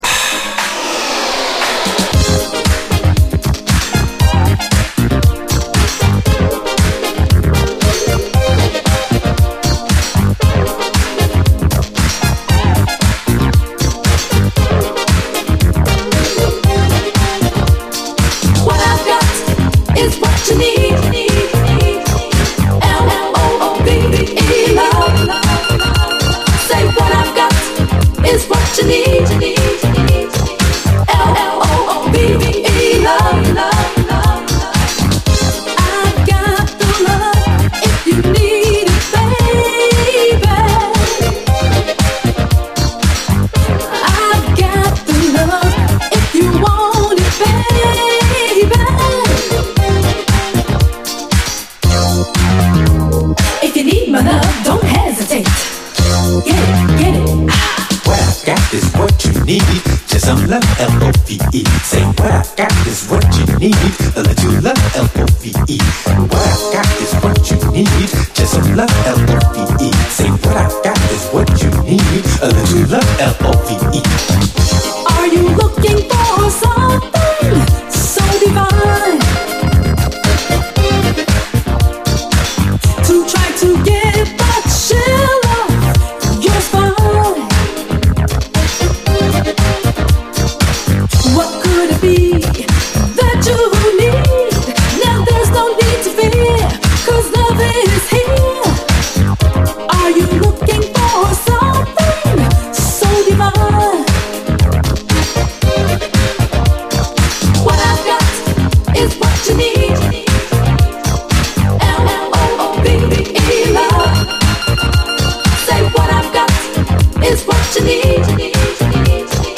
DISCO, 7INCH